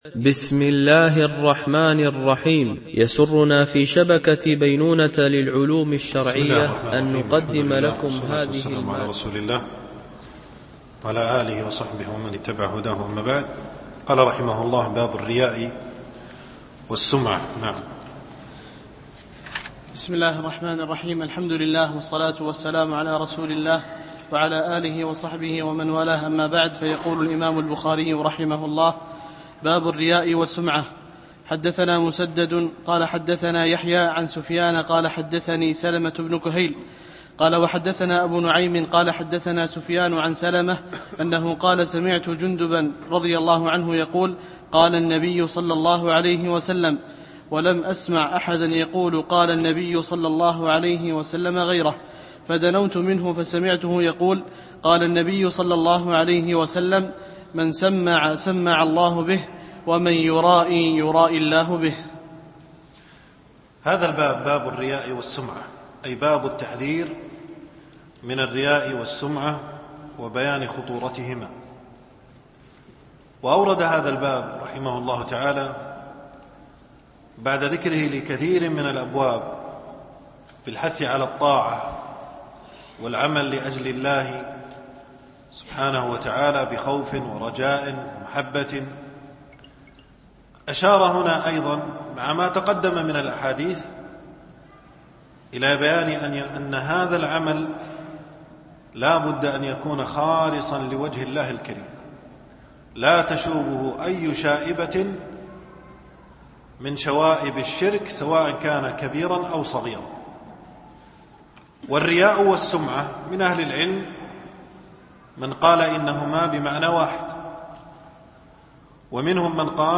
الدرس 6